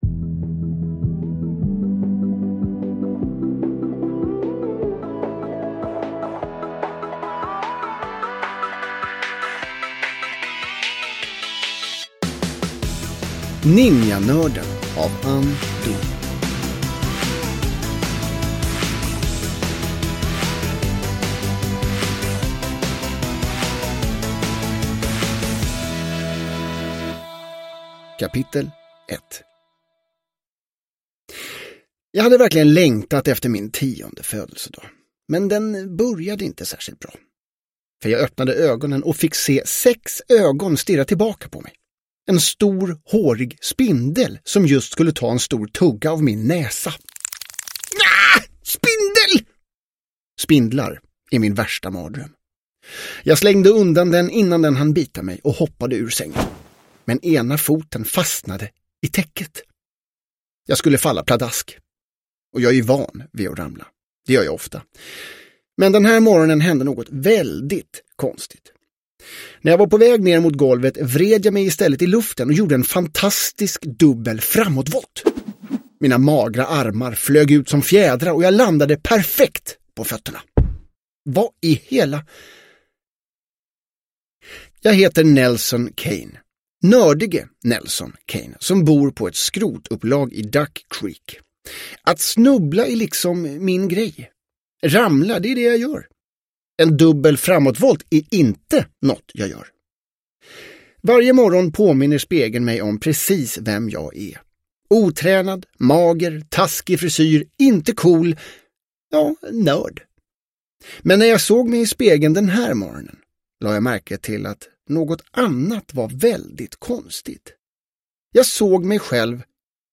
Ninjanörden – Ljudbok – Laddas ner